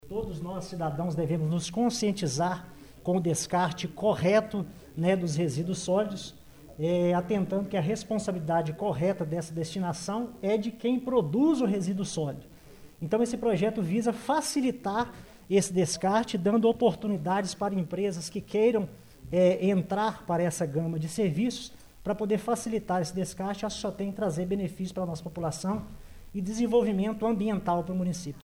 O Portal GRNEWS acompanhou no plenário da Câmara de Vereadores de Pará de Minas mais uma reunião ordinária na noite desta Quarta-Feira de Cinzas, 02 de março.